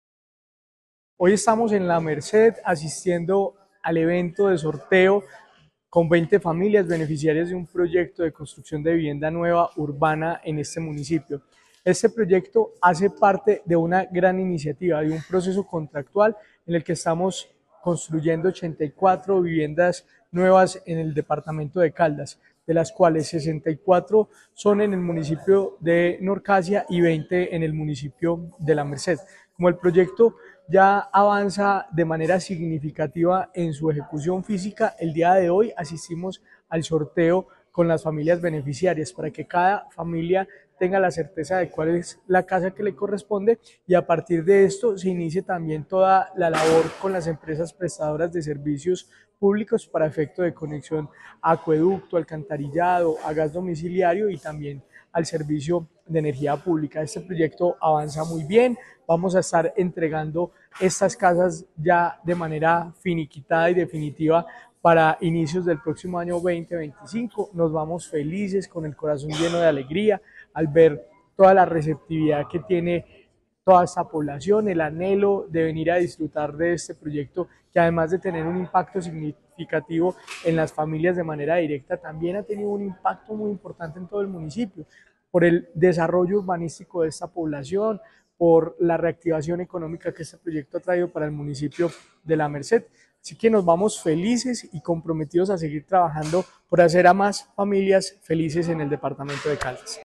Jorge William Ruiz Ospina, secretario de Vivienda y Territorio de Caldas.
Jorge-William-Ruiz-Ospina-Secretario-de-vivienda-de-Caldas-Sorteo-de-vivienda-La-Merced.mp3